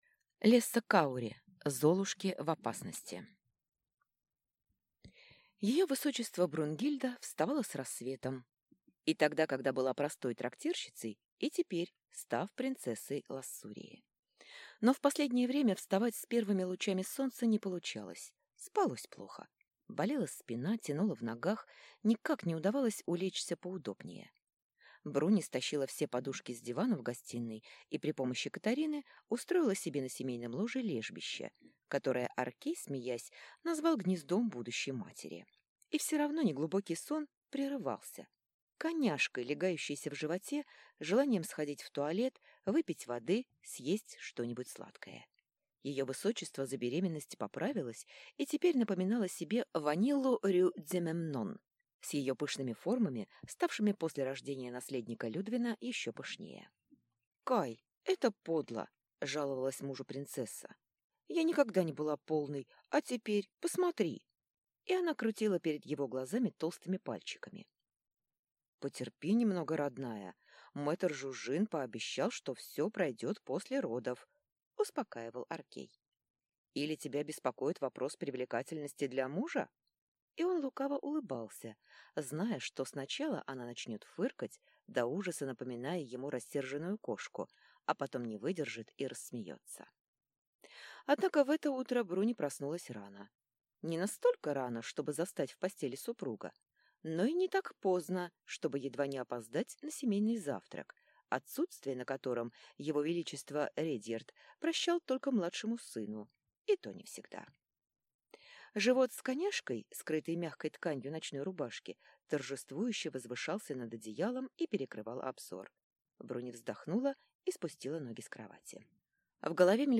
Аудиокнига Золушки в опасности | Библиотека аудиокниг